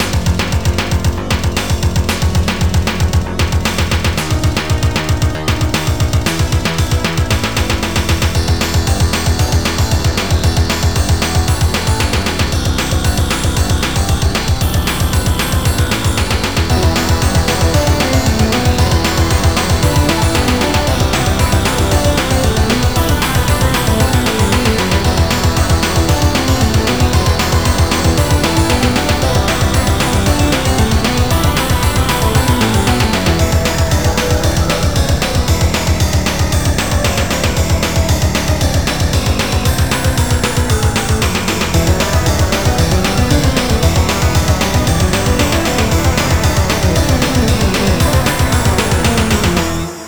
It was meant to be a boss theme that is loopable.
The song is relatively short, but it was meant to be looped.